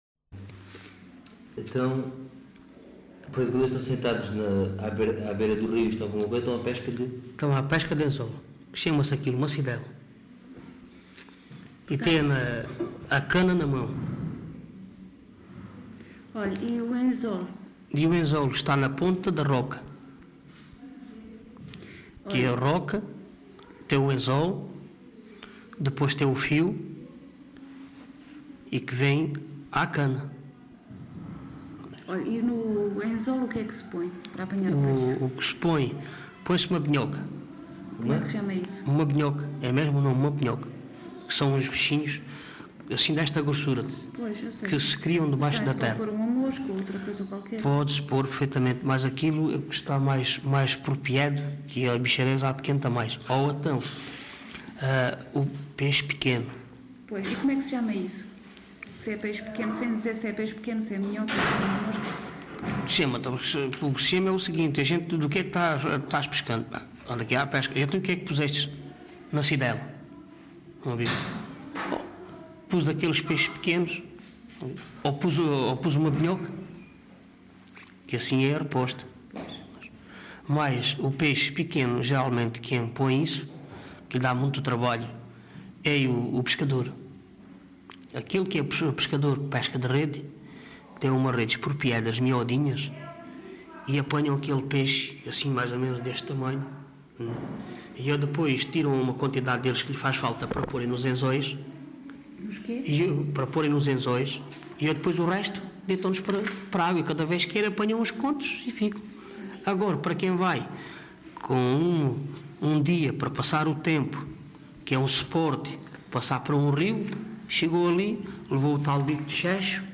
LocalidadeSerpa (Serpa, Beja)